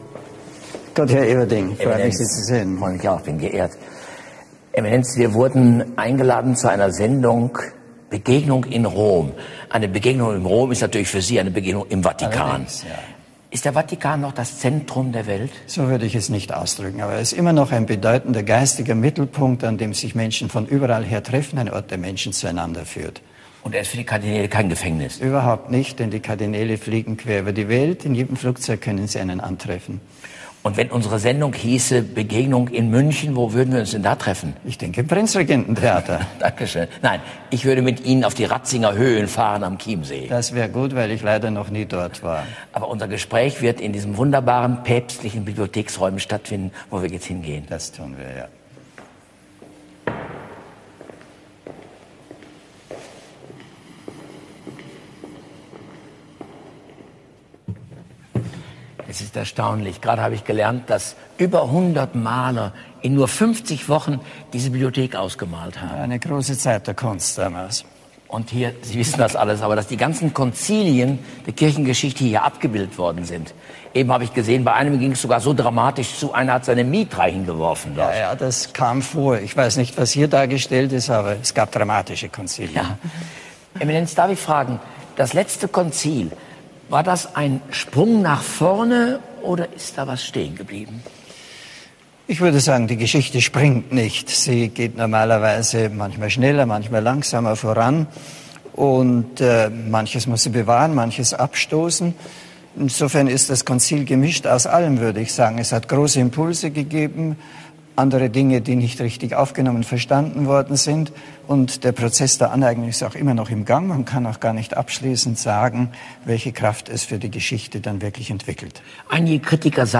Interview mit Kardinal Joseph Ratzinger (1998) ~ Katholische Predigten & Vorträge Podcast
Geführt von August Everding.
InterviewJosephRatzinger.mp3